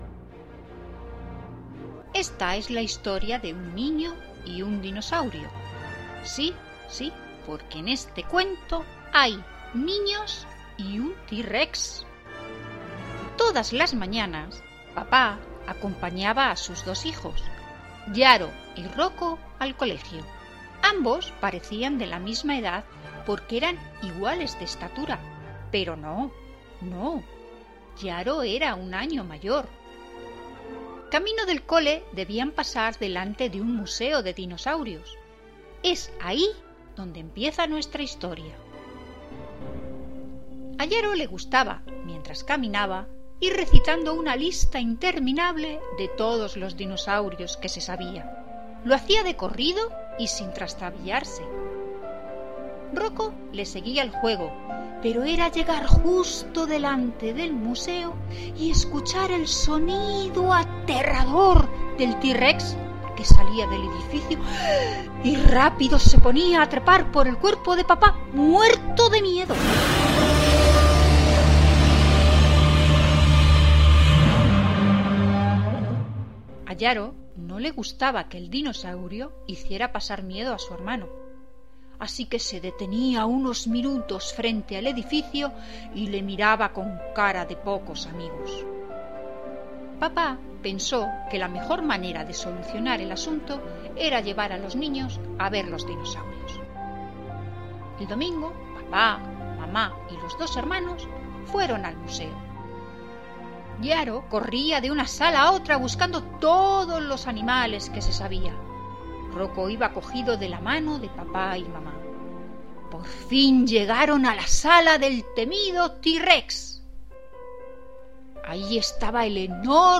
Encantador cuento infantil y mas encantadora; la voz de la narradora.